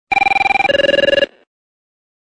座机铃声二（外线打来）